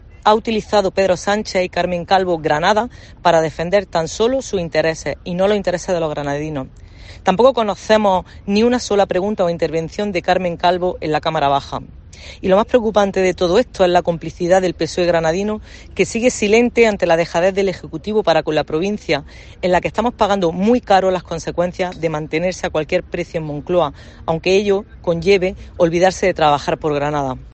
La diputada Lourdes Ramírez analiza el nombramiento de Carmen Calvo como presidenta del Consejo